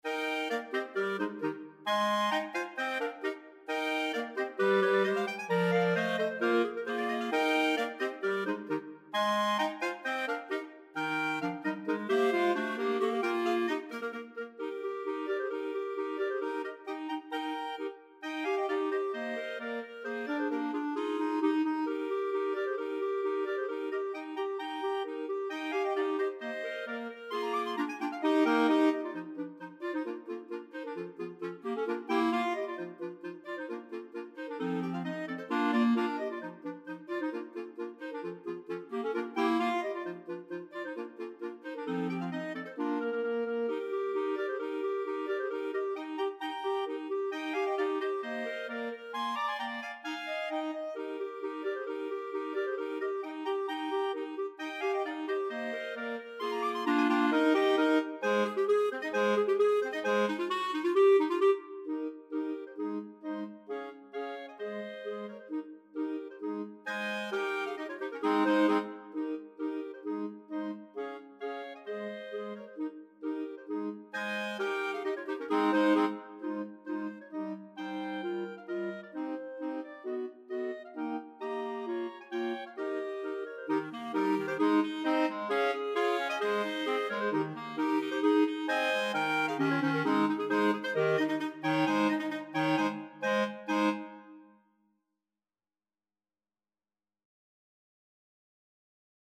Voicing: Mixed Clarinet Quartet